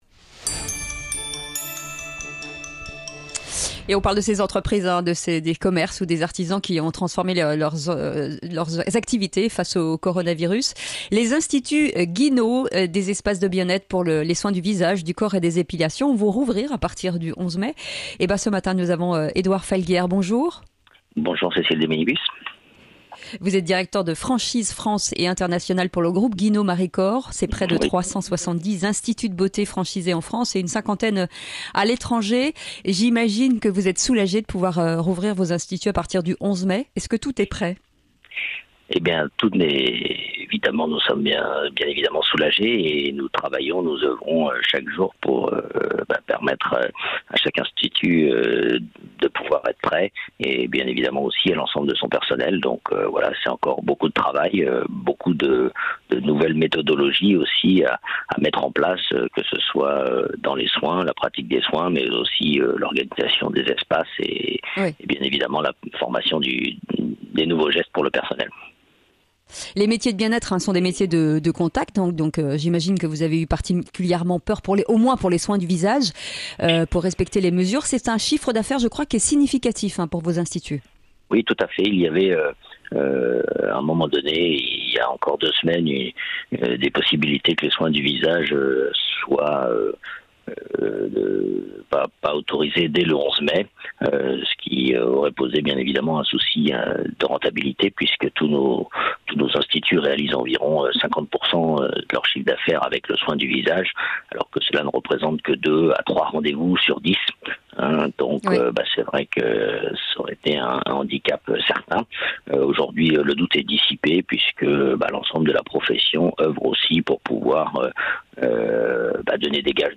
Sud Radio à votre service dans Le Grand Matin Sud Radio à 6h50 avec FIDUCIAL.